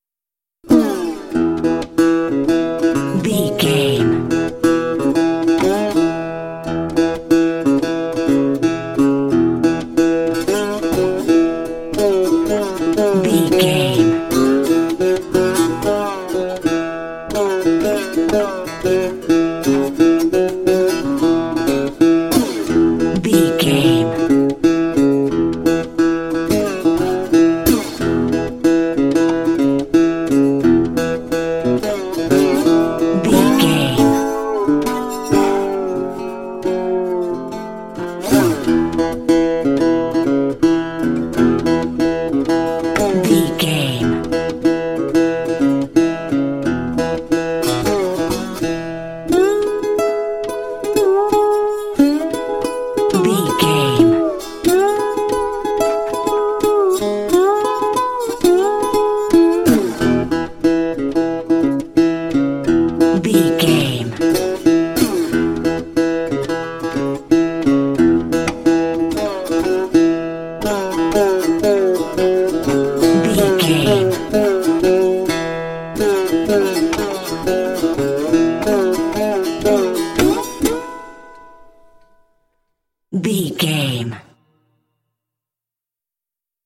Uplifting
Mixolydian
playful
acoustic guitar
country
bluegrass